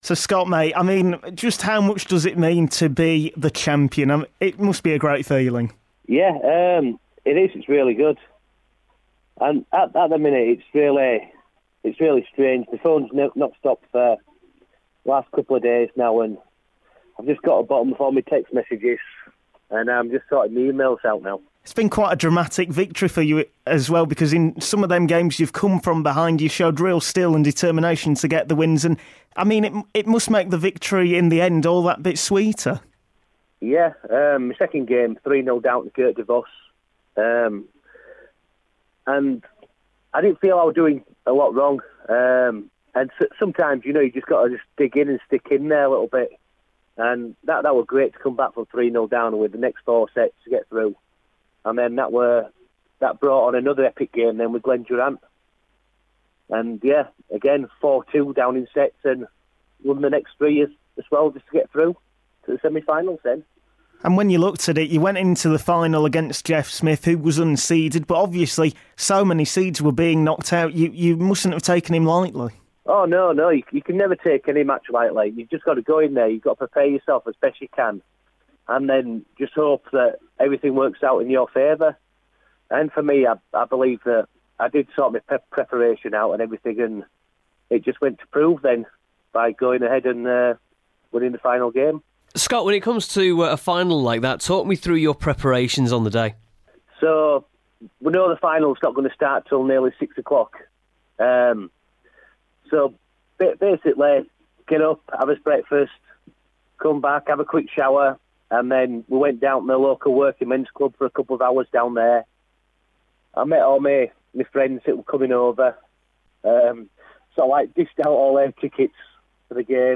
The new BDO world darts champion Scott Waites talks to Radio Yorkshire